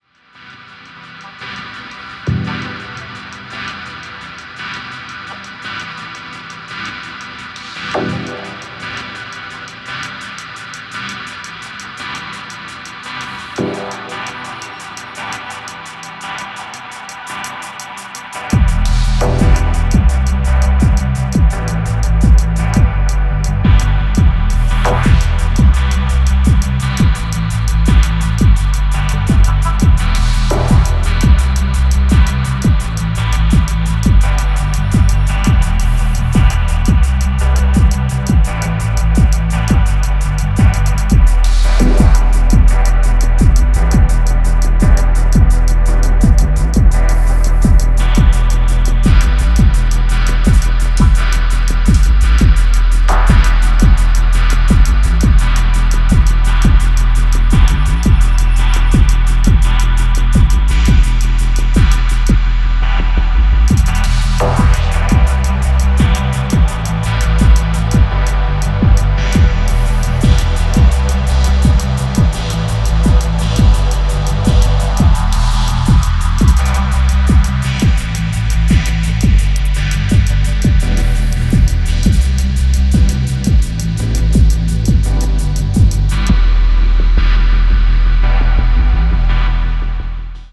ダブ・テクノ的コードとヘヴィなサブベースの荘厳な合奏
非常に先進的、かつ神聖さすら感じさせるDNB表現を堂々開陳